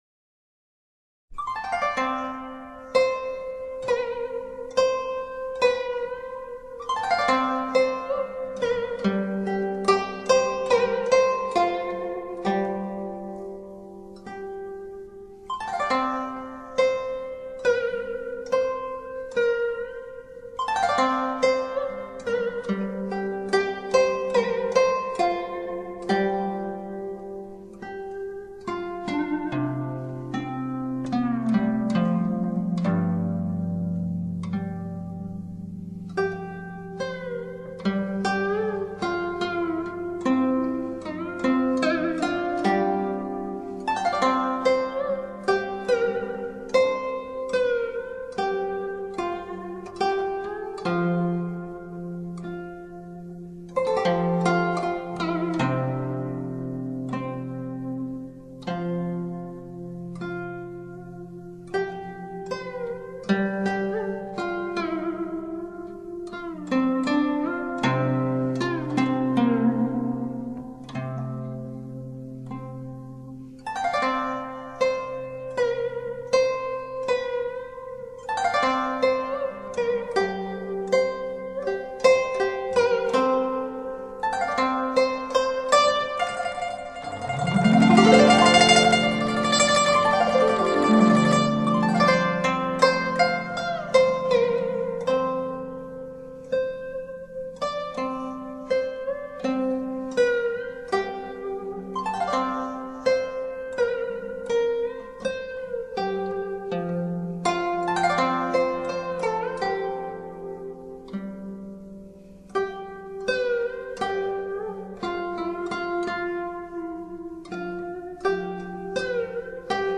本碟收录了我国优秀的民族乐曲，淡而清雅，可作为肘边首选的时尚休闲音乐！